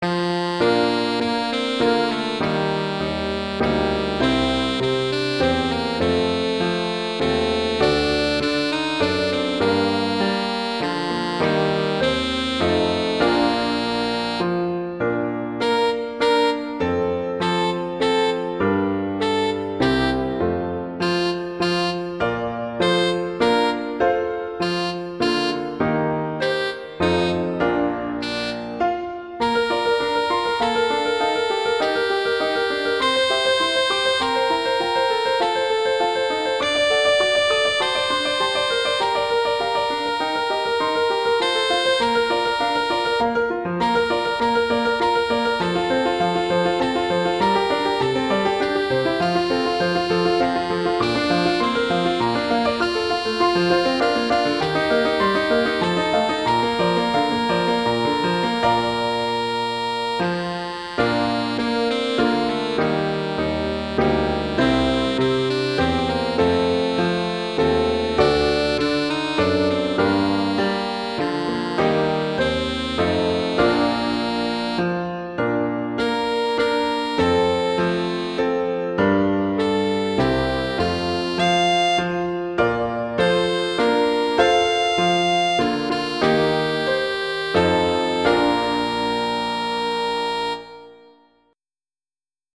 arrangements for alto saxophone and piano
alto saxophone and piano